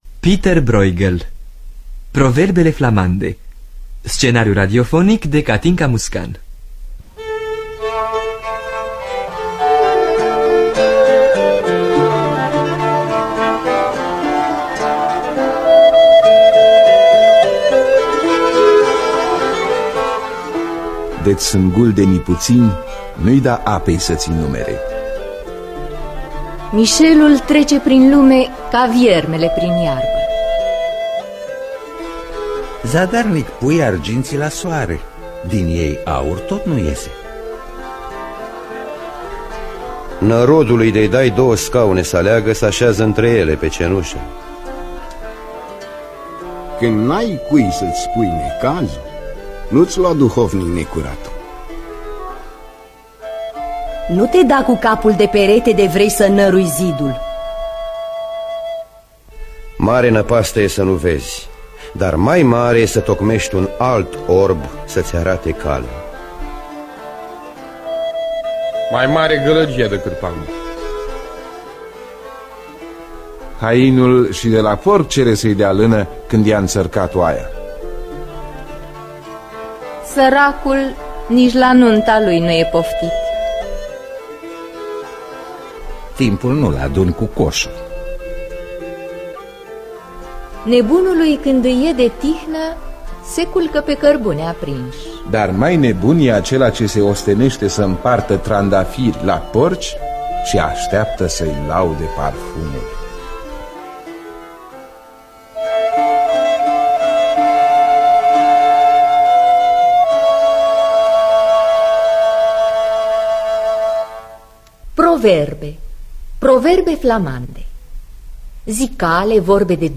Scenariu radiofonic de Catinca Muscan.